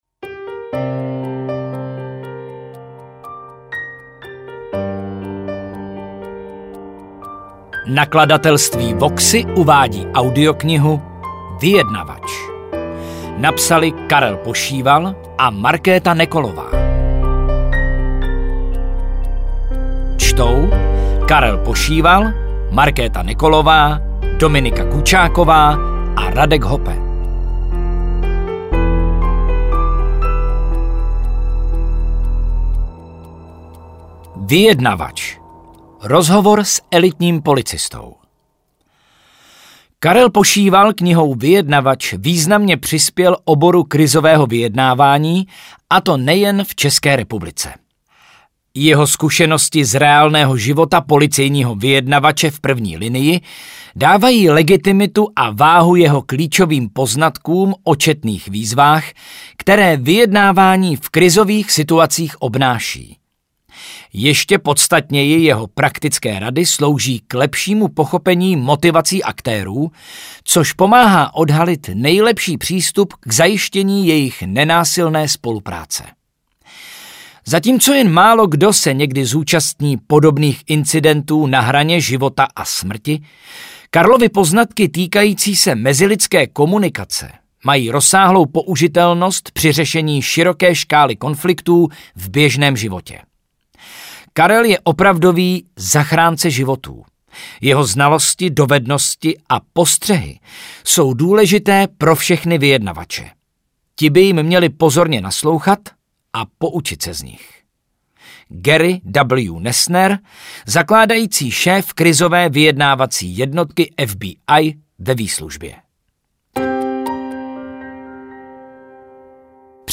AudioKniha ke stažení, 18 x mp3, délka 5 hod. 1 min., velikost 274,5 MB, anglicky